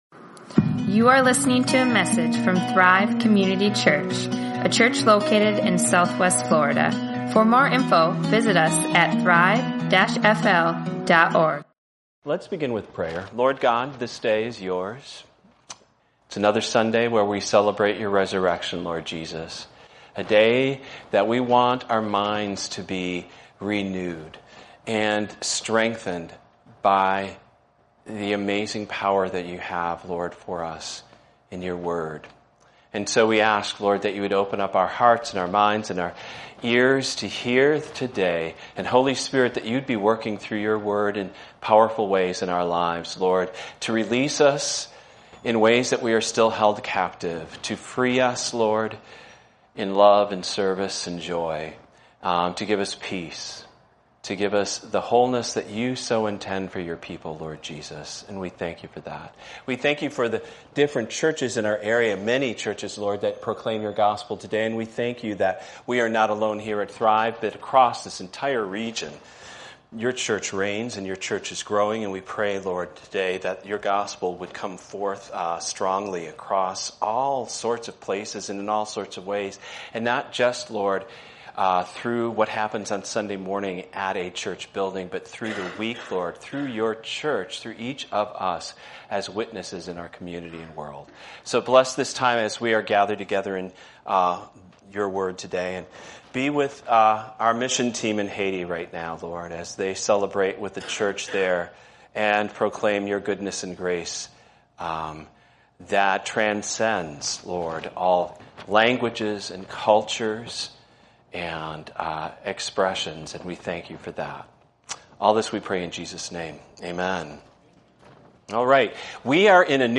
Mastermind | Sermons | Thrive Community Church